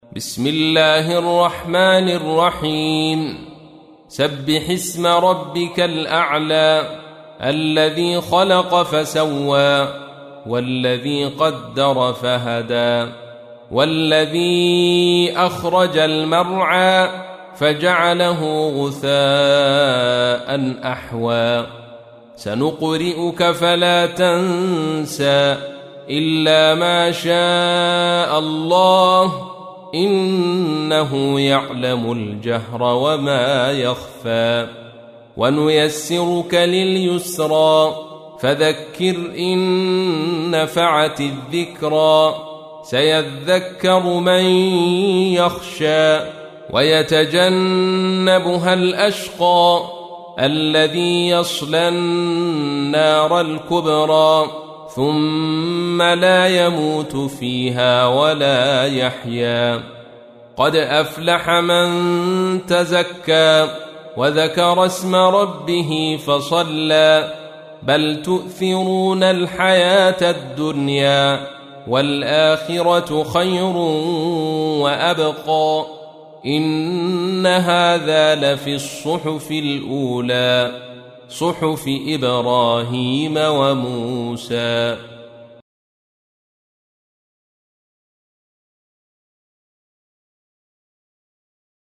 تحميل : 87. سورة الأعلى / القارئ عبد الرشيد صوفي / القرآن الكريم / موقع يا حسين